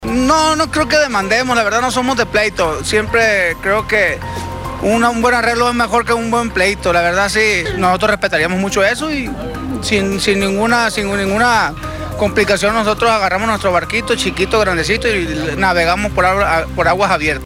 Responden los integrantes de Banda Tierra Sagrada